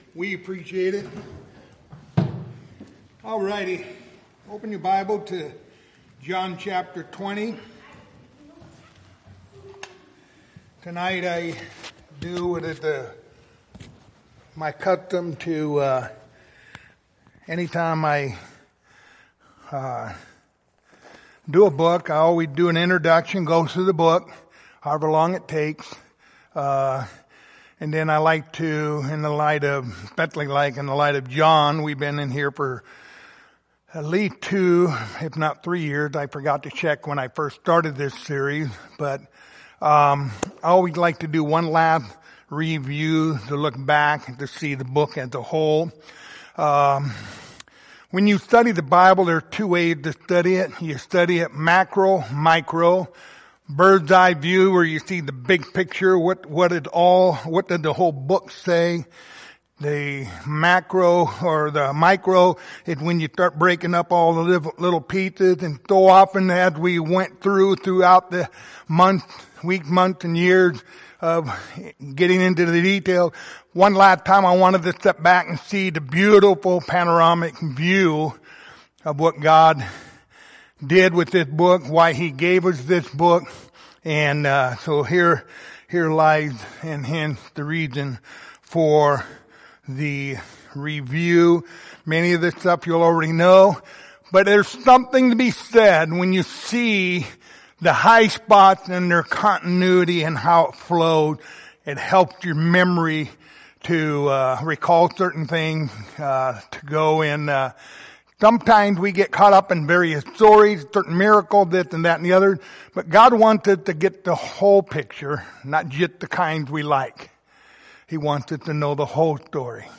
Passage: John 20:31 Service Type: Wednesday Evening Topics